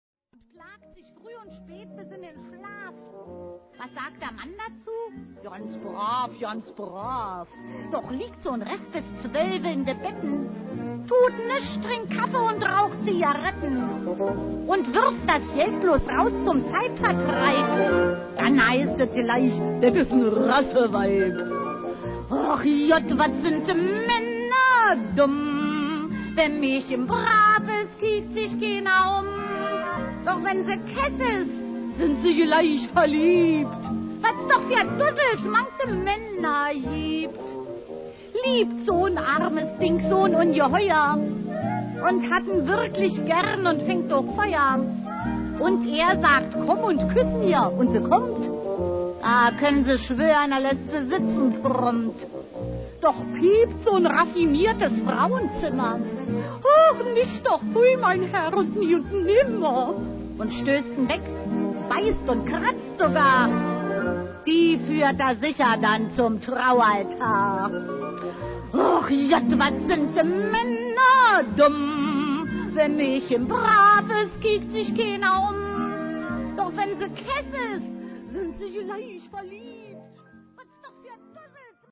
Unter bearbeitet wurde das Rauschen entfernt.